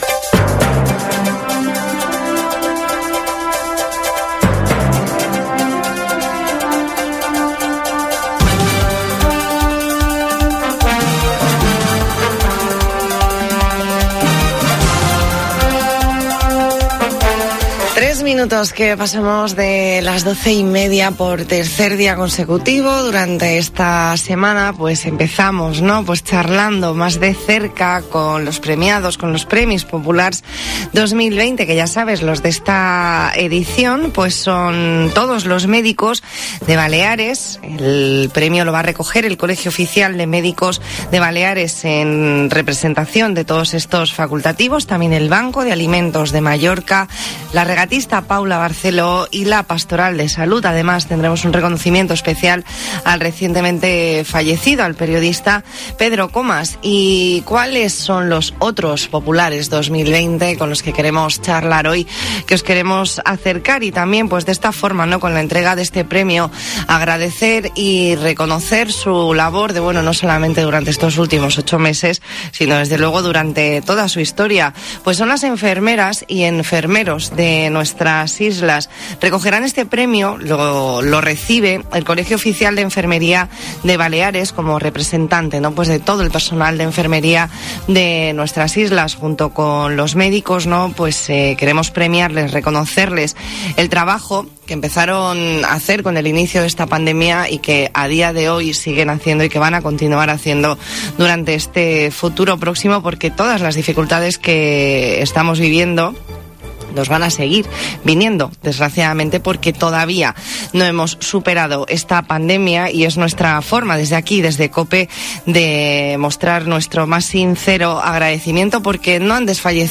Entrevista en La Mañana en COPE Más Mallorca, miércoles 25 de noviembre de 2020.